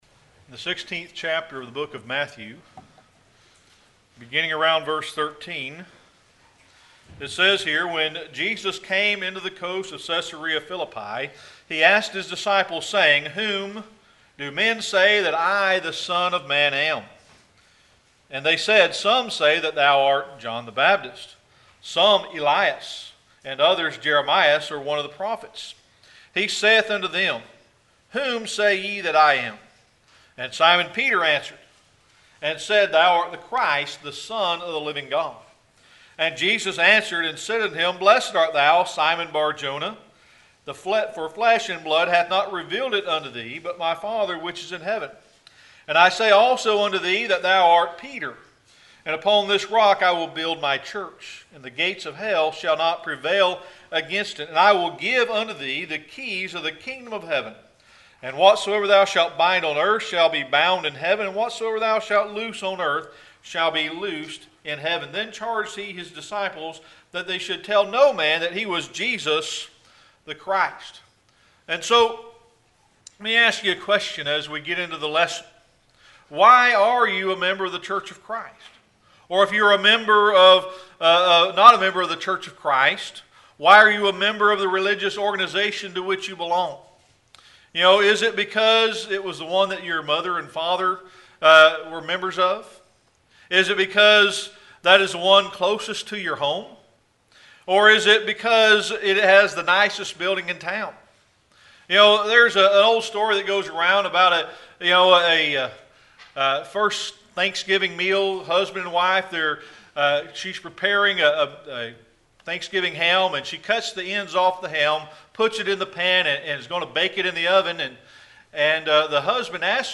Matthew 16 Service Type: Sunday Morning Worship Matthew 16:13-18 Why are you a member of the church of Christ?